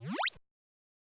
speedUp.wav